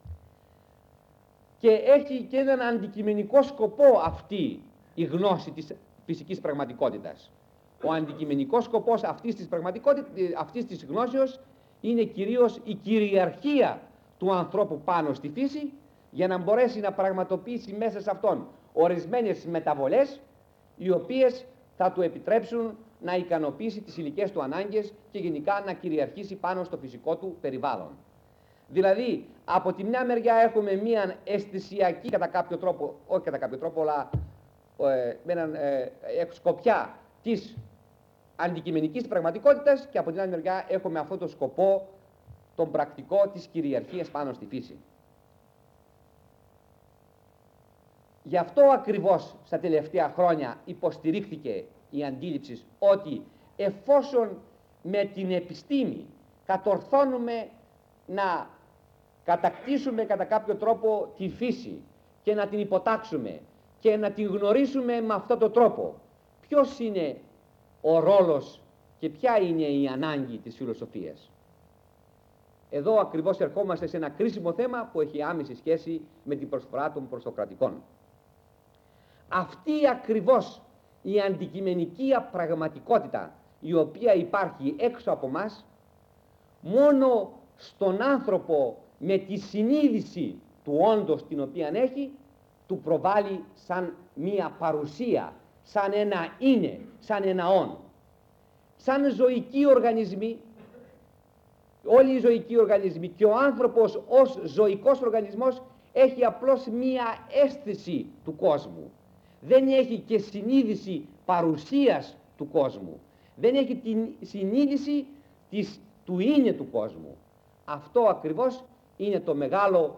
Λέξεις-Κλειδιά: κύκλος μαθημάτων; ηράκλειτος